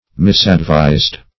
Misadvised \Mis`ad*vised"\